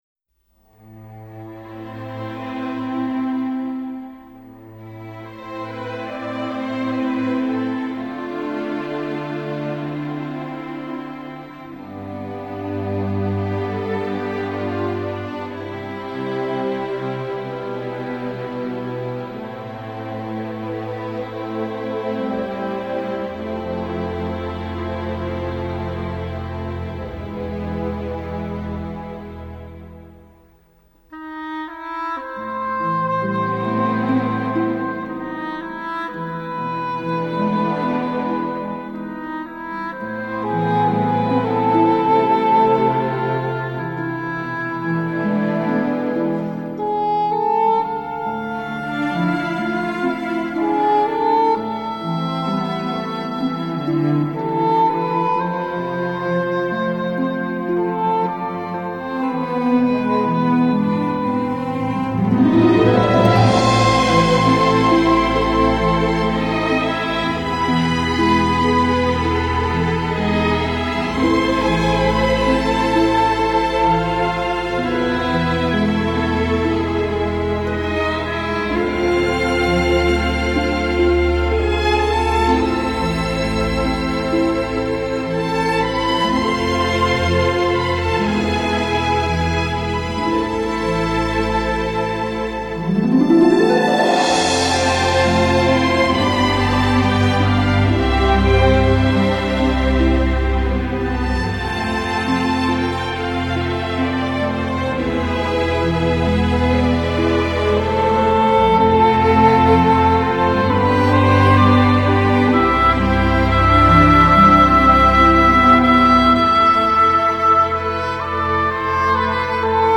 类别:电影原声
木管（长笛、双簧管）音色仍是构成乐曲忧郁气质的主要来源